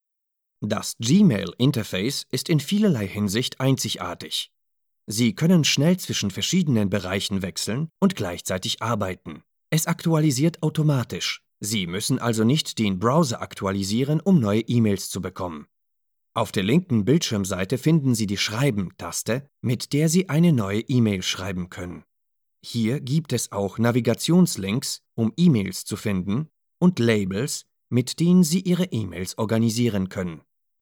Almanca Seslendirme
Erkek Ses